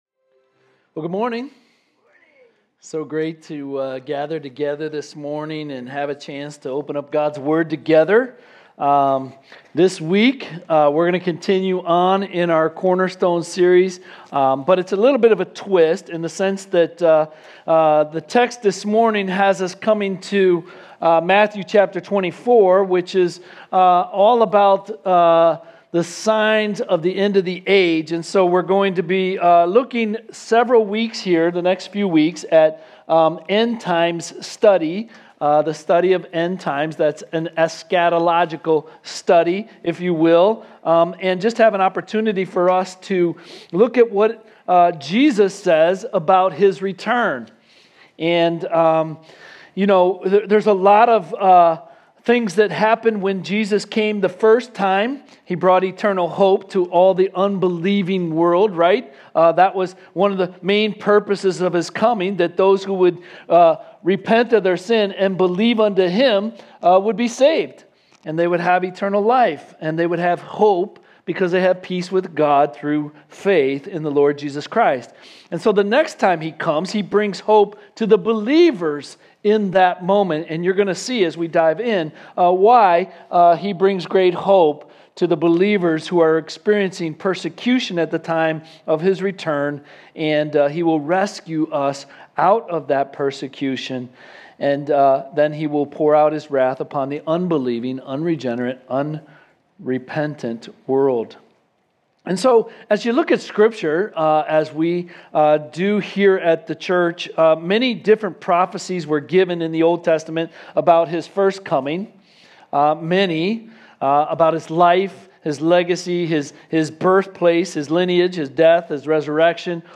Home Sermons Cornerstone